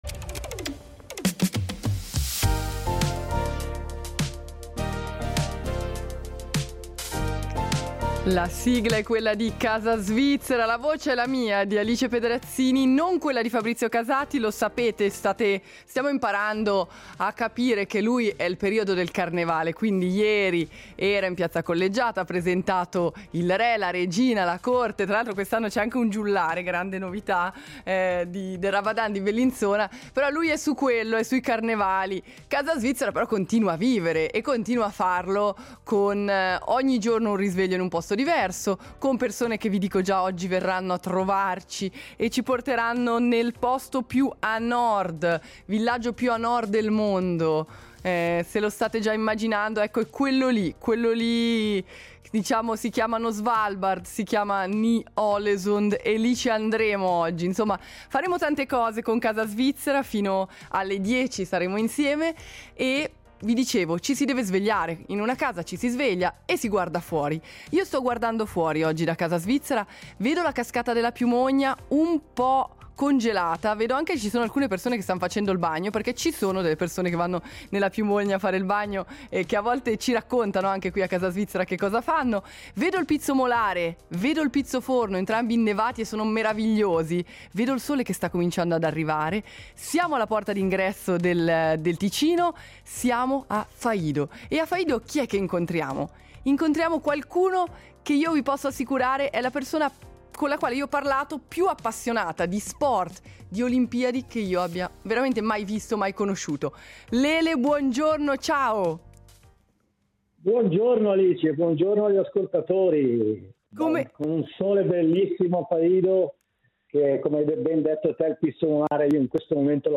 Un luogo estremo dove il cambiamento climatico non è teoria, ma realtà quotidiana. In studio con noi